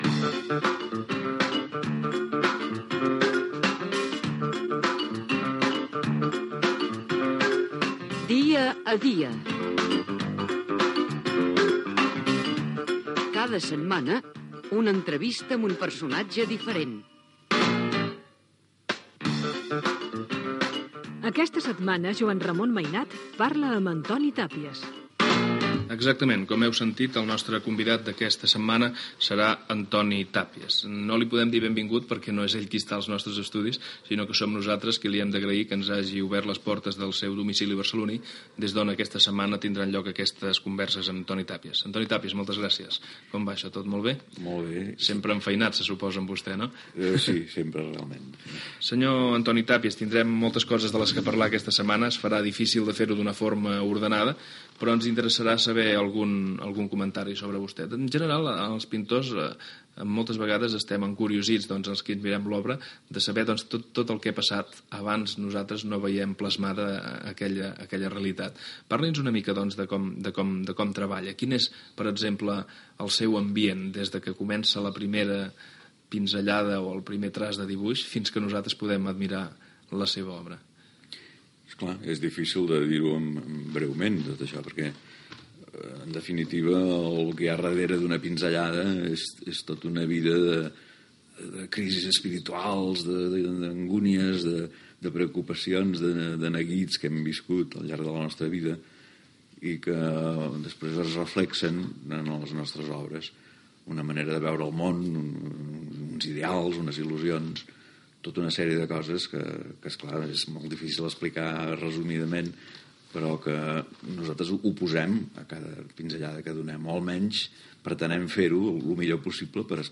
Careta del programa i entrevista al pintor Antoni Tàpies, feta a la seva casa de Barcelona. Explica la seva vivença com a pintor
Divulgació